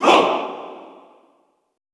MALE HOO  -R.wav